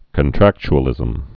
(kŏn-trăkch-ə-lĭzəm)